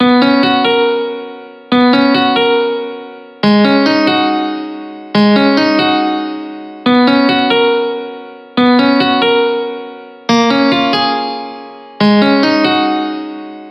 Crazy Series Dubstep Piano
描述：dubstep paino loop i made, loosely based around my first hip hop piano loop, only in major chords instead of minor so it fields a bit brighter and ... uhhmm.. happier ?
标签： 140 bpm Dubstep Loops Piano Loops 1.15 MB wav Key : Unknown
声道立体声